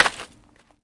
描述：Gravel使用Dynasonic PDR1在瑞典Kilanäsan录制。
标签： 场记录 砾石 石块 卵石
声道立体声